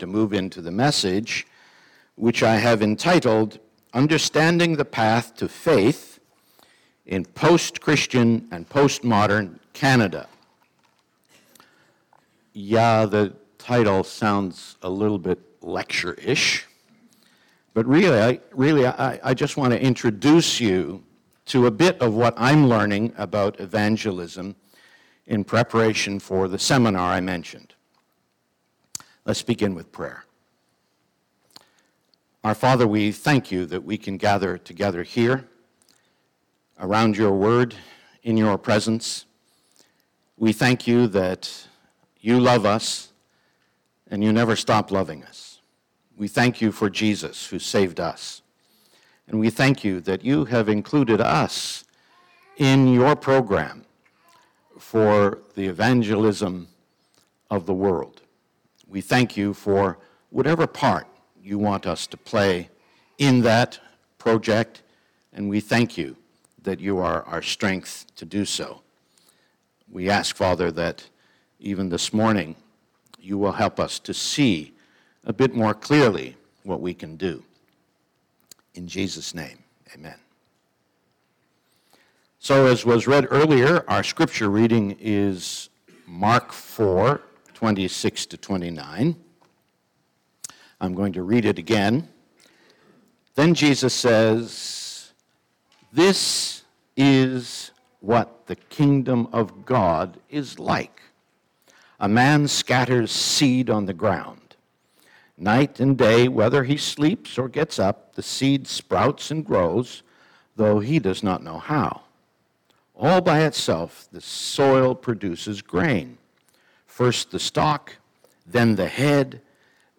Passage: Mark 4::26-29 Service Type: Sermon